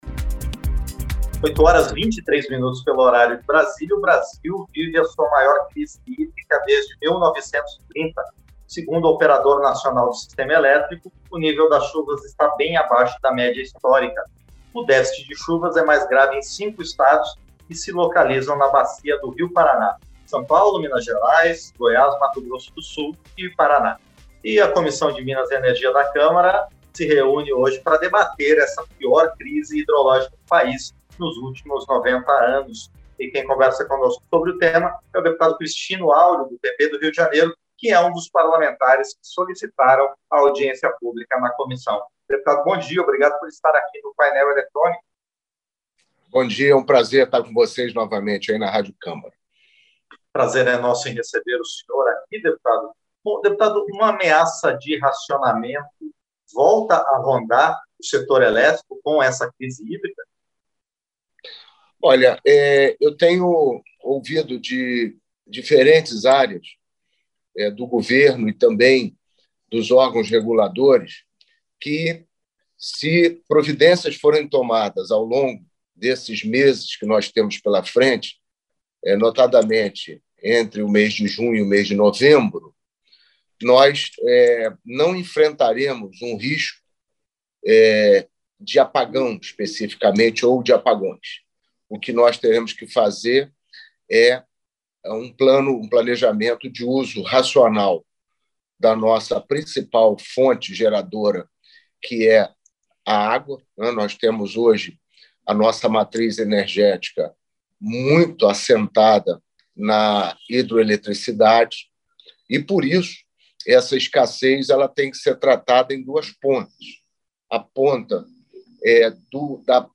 Entrevista - Dep. Christino Áureo (PP-RJ)